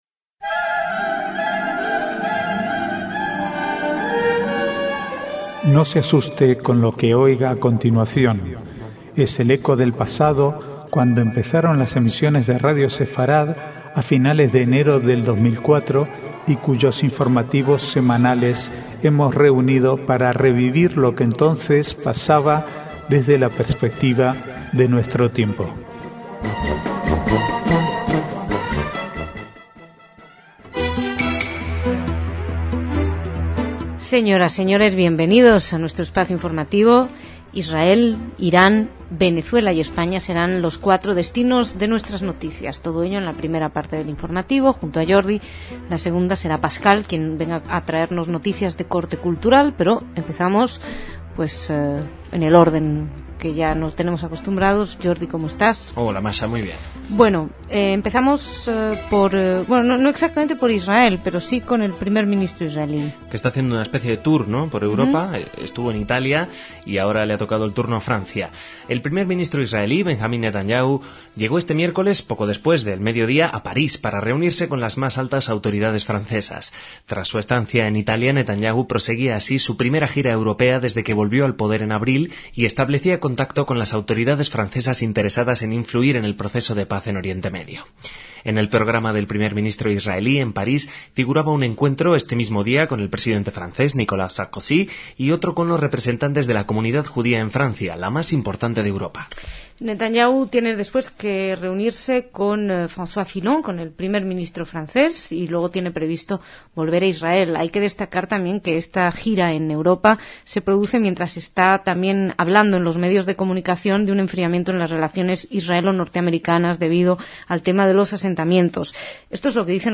Archivo de noticias del 25 al 30/6/2009